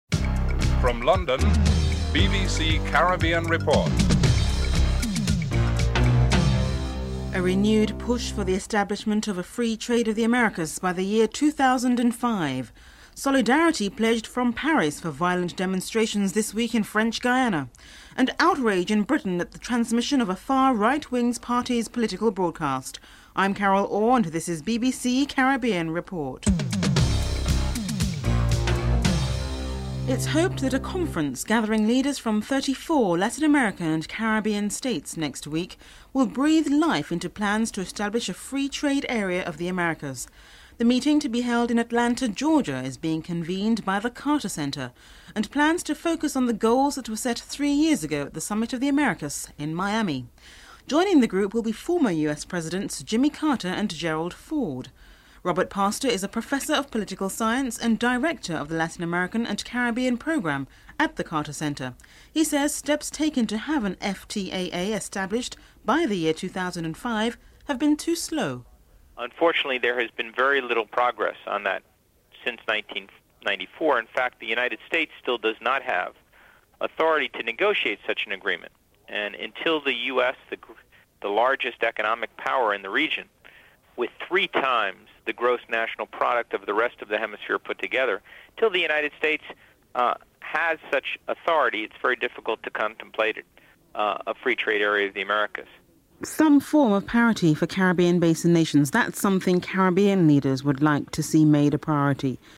1. Headlines (00:00-00:29)